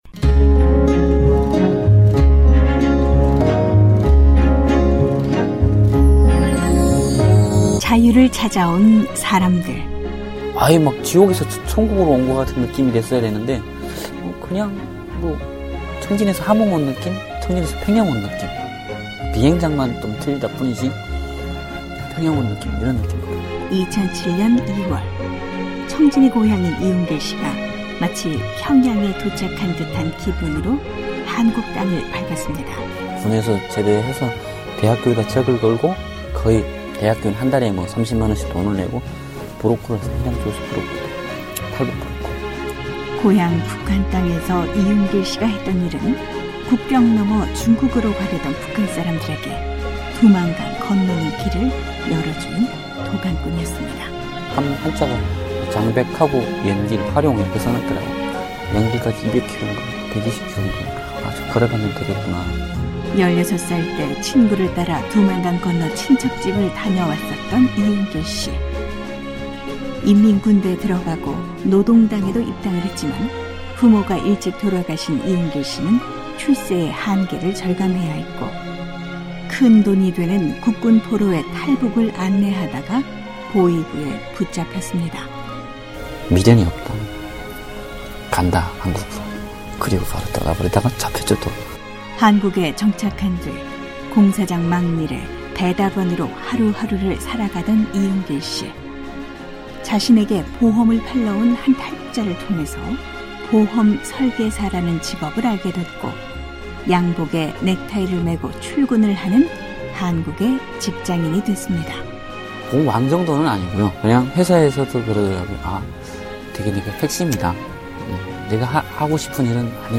자신도 모르게 잊혀져 가는 청진말씨로 고향의 친구들에게 안부를 전한다.